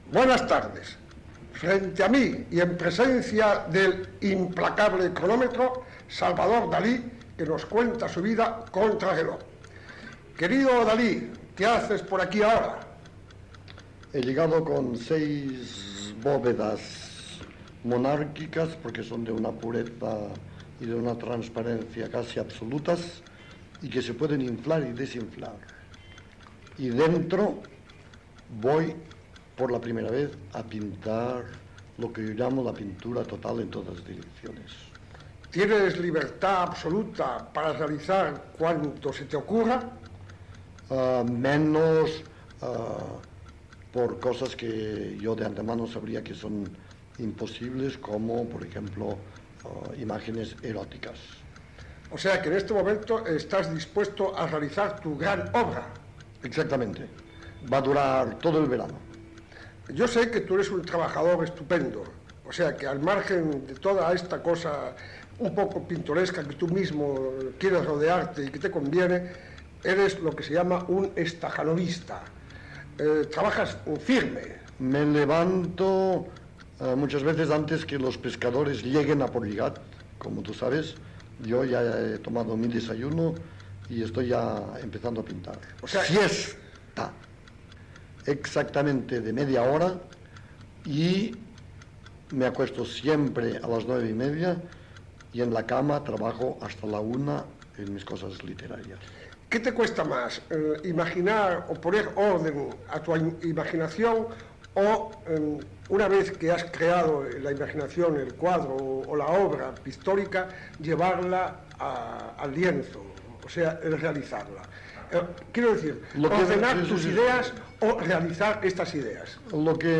Entrevista al pintor Salvador Dalí feta a l'Hotel Ritz de Barcelona.